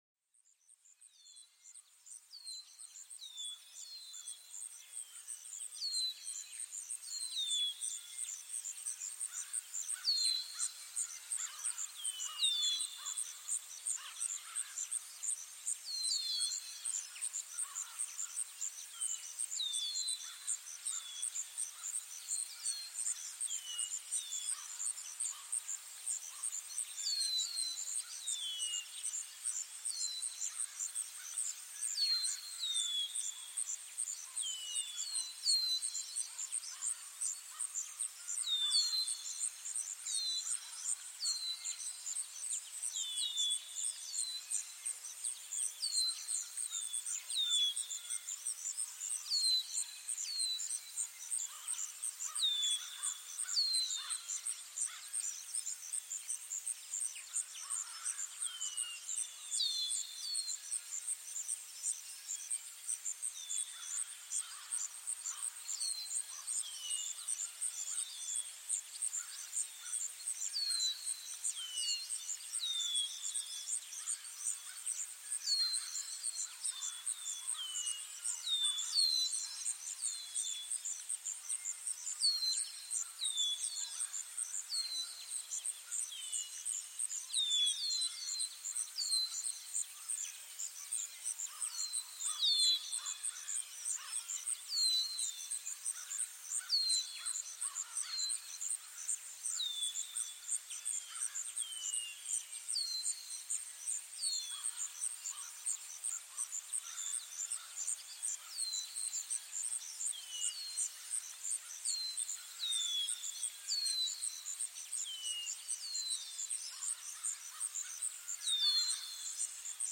Sumérgete en la atmósfera calmante de un bosque, donde el susurro de las hojas y el canto de los pájaros crean un refugio de paz, este episodio te invita a explorar la belleza y la serenidad del bosque, un lugar donde la naturaleza habla y el tiempo parece detenerse, déjate llevar por los sonidos armoniosos del bosque, una experiencia auditiva que revitaliza el alma y enriquece la mente.Este podcast es una experiencia de audio inmersiva que sumerge a los oyentes en los maravillosos sonidos de la naturaleza.
SONIDOS DE LA NATURALEZA PARA LA RELAJACIÓN